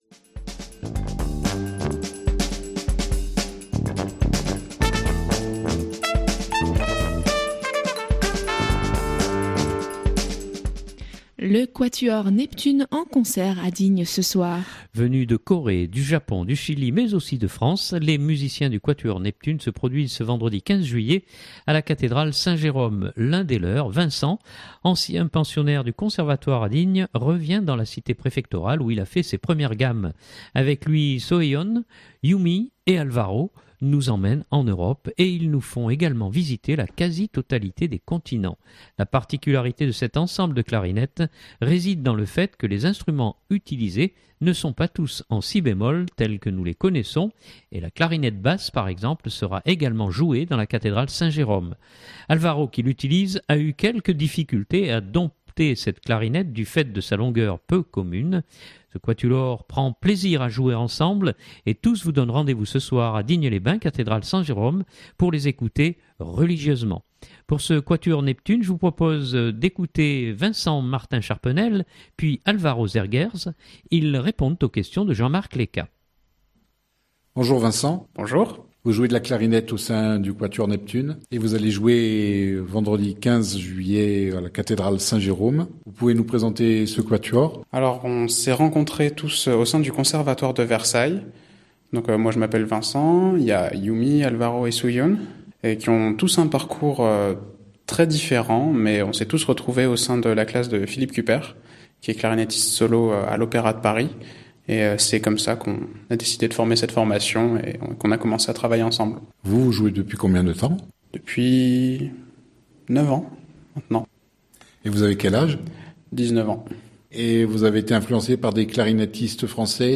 ils répondent aux questions